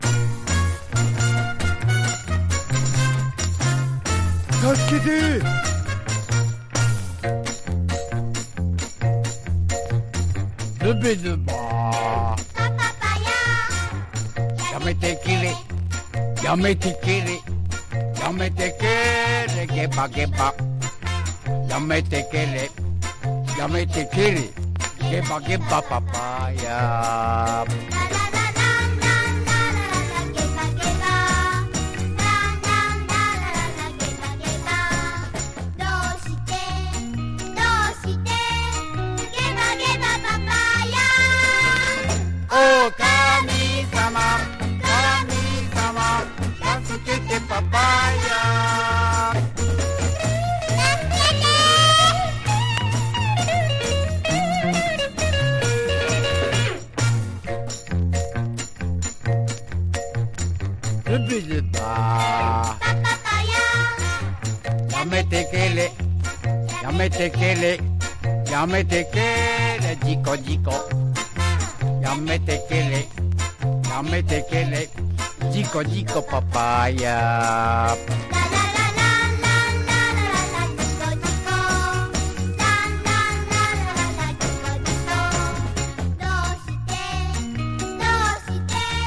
和モノ / ポピュラー